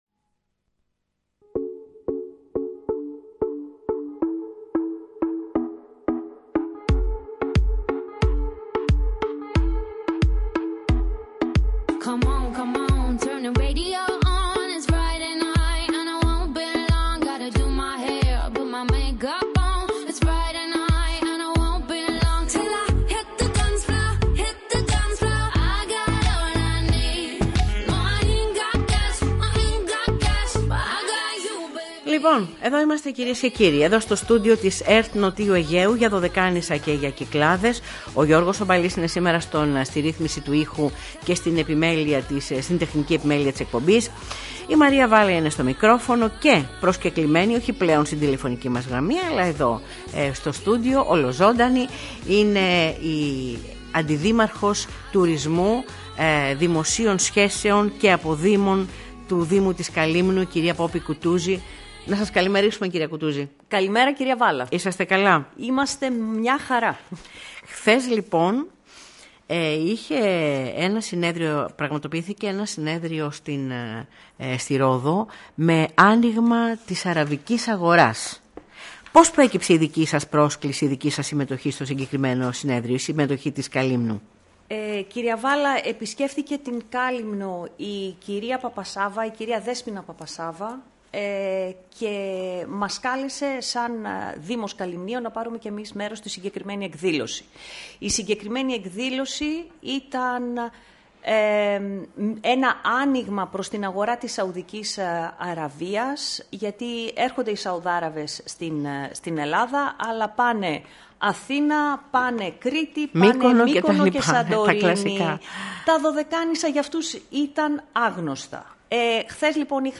Η Αντιδήμαρχος Τουρισμού, Δημοσίων Σχέσεων και Αποδήμων Δήμου Καλυμνίων κ. Καλλιόπη Κουτούζη, σε συνέντευξή της στην εκπομπή ΡΑΔΙΟΠΛΟΕΣ αναφέρθηκε στη σημασία των επαφών της με στελέχη της μεγαλύτερης εταιρείας Tour Operators στη Σαουδική Αραβία, με την ευκαιρία της επισκέψεώς τους στη Ρόδο, στην αλλαγή του τουριστικού μέλλοντος της Καλύμνου με ενδεχόμενη ευόδωση έλευσης επισκεπτών από τη νέα αυτή αγορά, που μέχρι τώρα, γνώριζε μόνο ελάχιστα μέρη της Ελλάδος ως προορισμό.